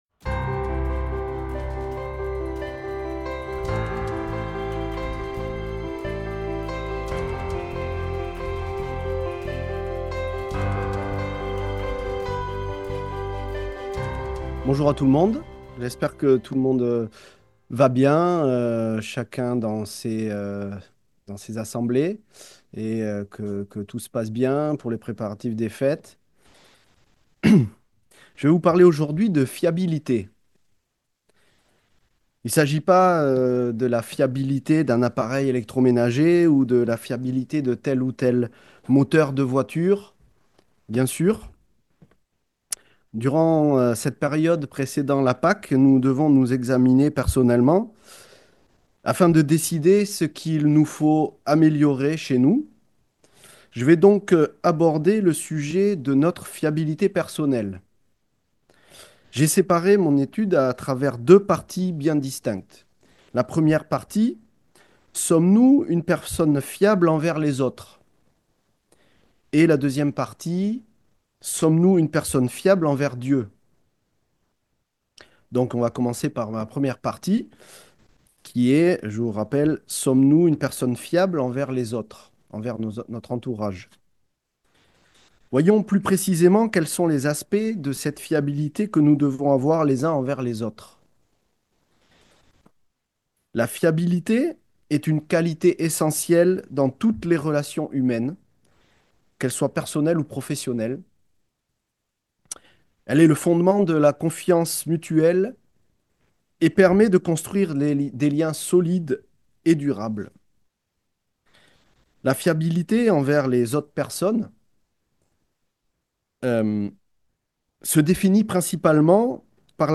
À l'approche de la Pâque, cette sermonette nous invite à examiner notre fiabilité personnelle sous deux angles : sommes-nous fiables envers les autres et envers Dieu ? Un rappel pratique des engagements pris lors du baptême et des clés pour améliorer notre constance dans nos relations humaines et spirituelles.
Given in Bordeaux